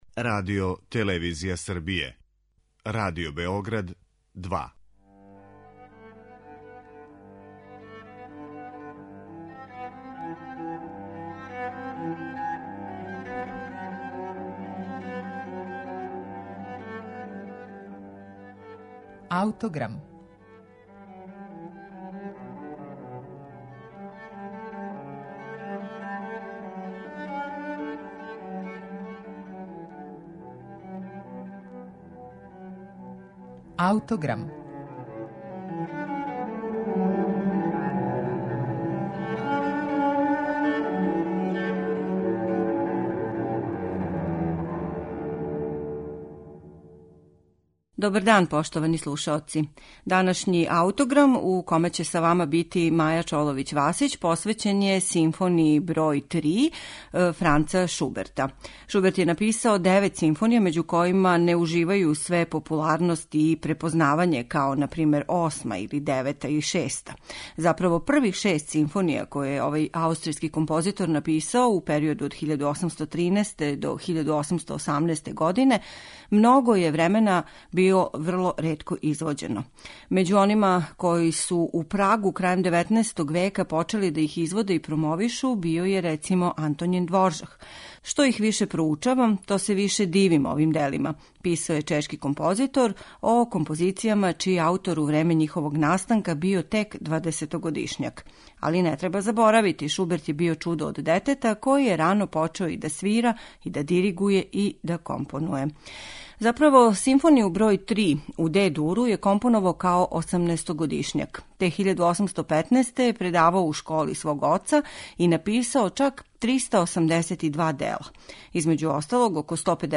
Емитоваћемо снимак Државне капеле из Дрездена, којом диригује Херберт Блумстет.